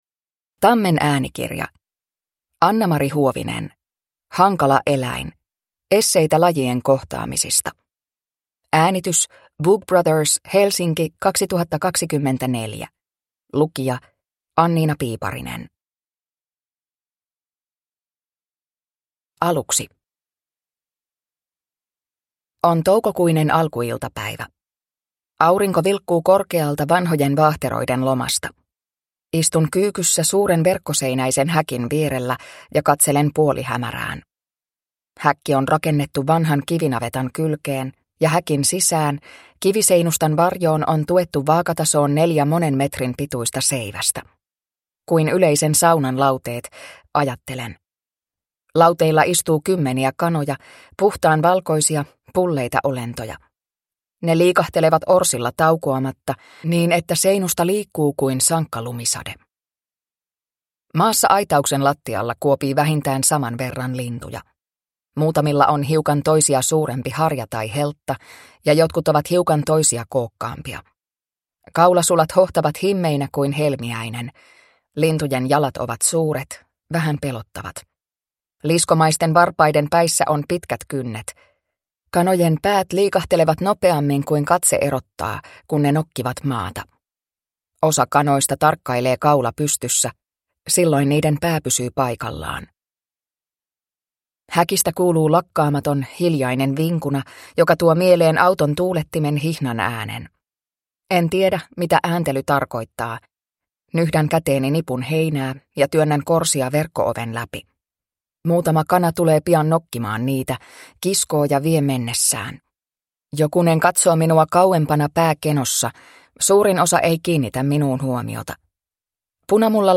Hankala eläin – Ljudbok